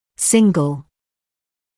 [‘sɪŋgl][‘сингл]один, единственный; одинарный; однократный